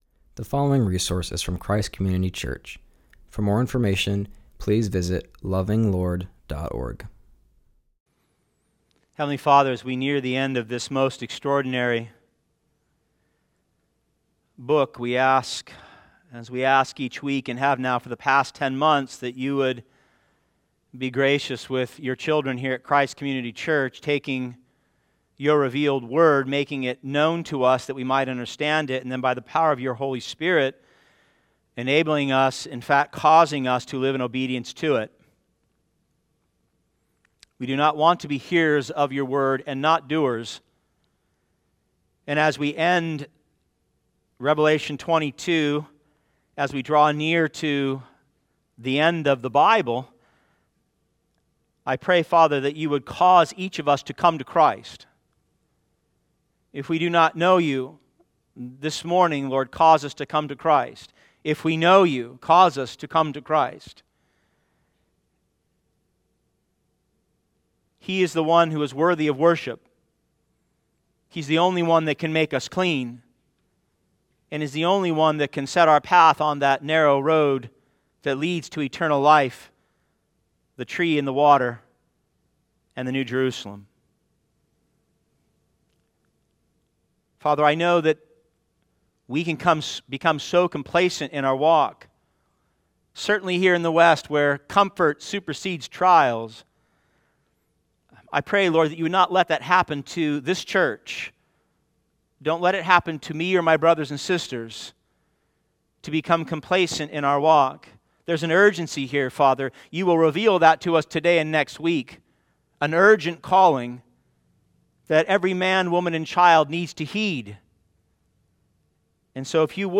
continues our study in Revelation by preaching on Revelation 22:14-17.